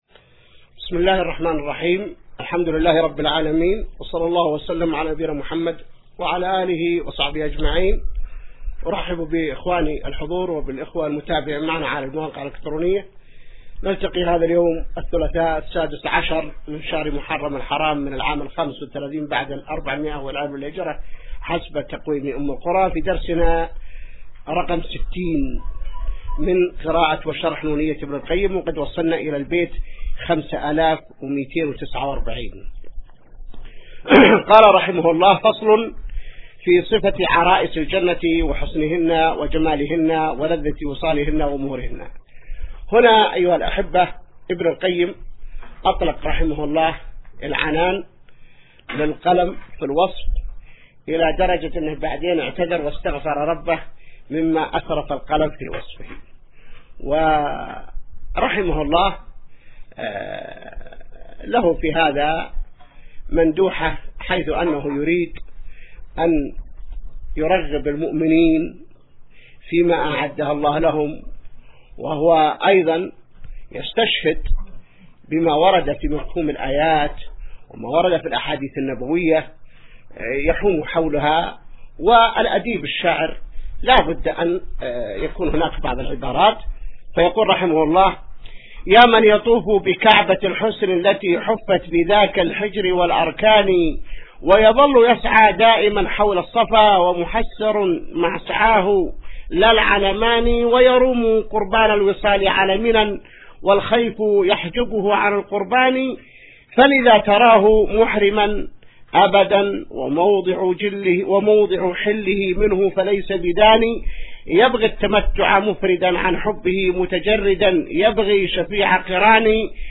الدرس 60 من شرح نونية ابن القيم | موقع المسلم